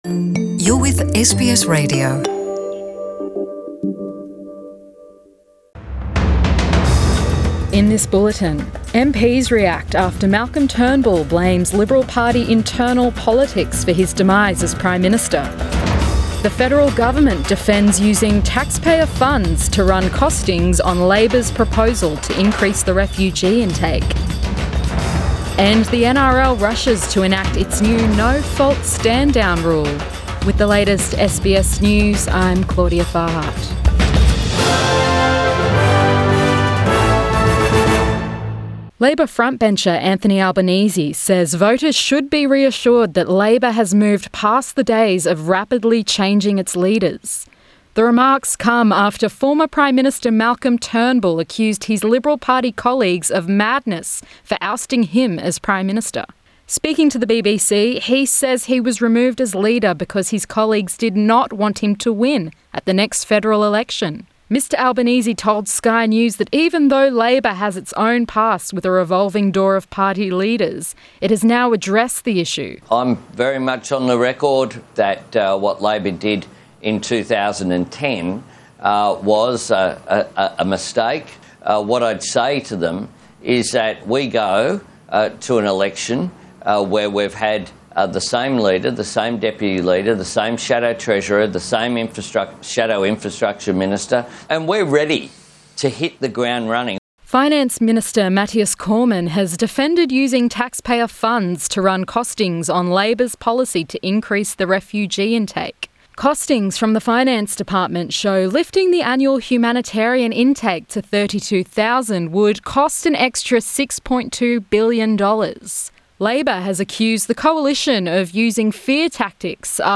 Midday bulletin 8 March